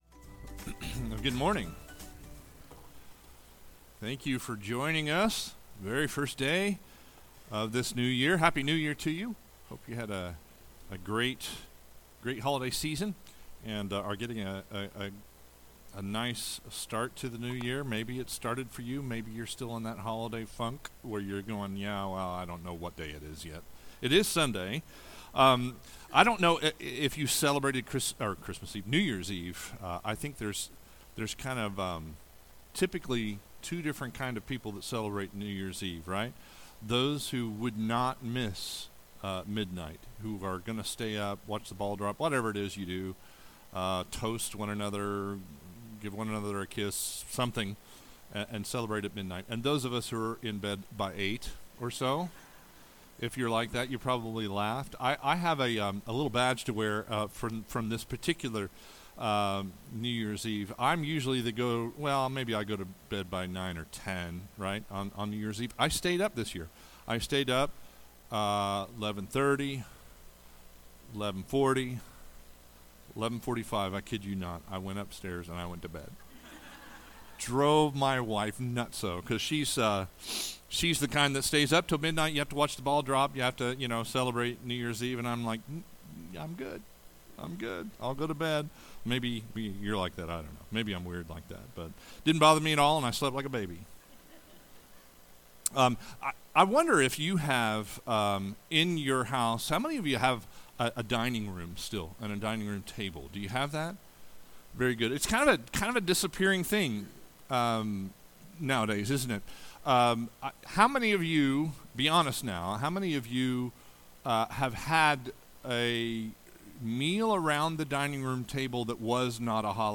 Sermons | Watermarke Church